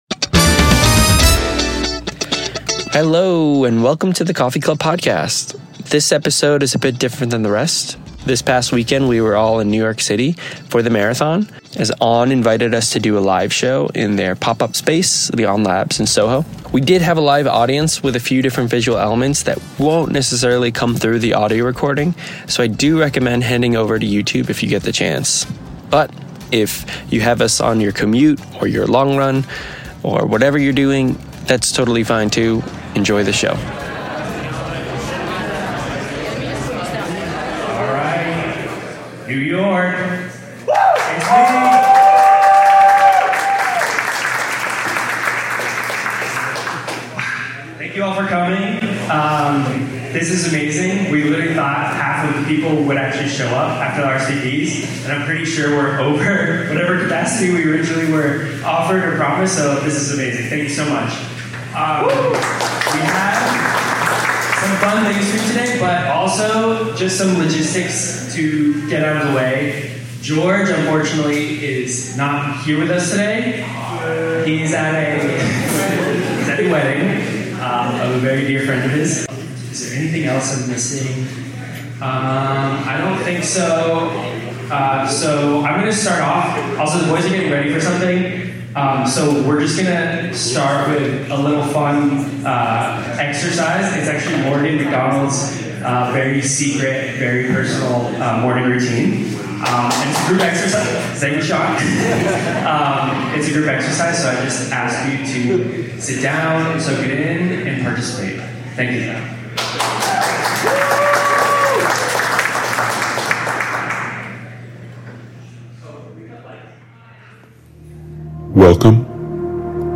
The boys head to NYC for this episode to do our very first live show with a studio audience. We had a great time with our fans in New York celebrating Hellen Obiri and On's marvelous new LightSpray technology.